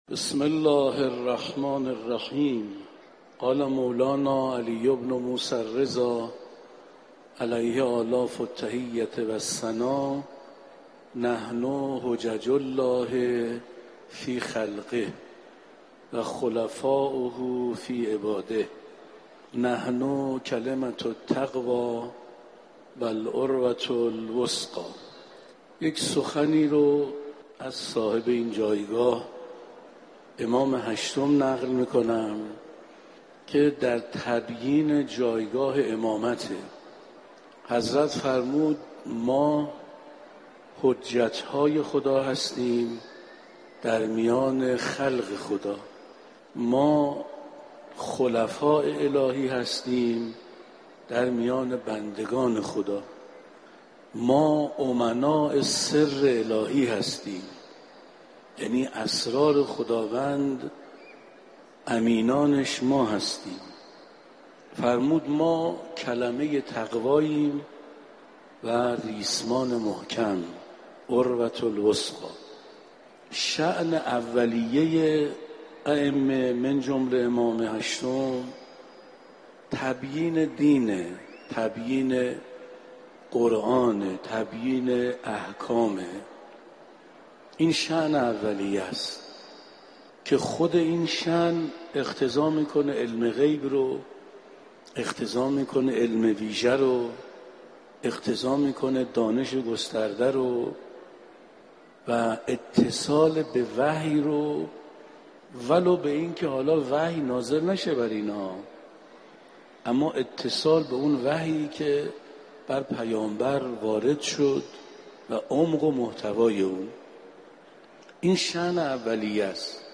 دسته بندی: صوت سخنرانی مذهبی و اخلاقی